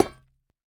Minecraft Version Minecraft Version latest Latest Release | Latest Snapshot latest / assets / minecraft / sounds / block / netherite / break4.ogg Compare With Compare With Latest Release | Latest Snapshot
break4.ogg